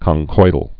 (kŏng-koidl)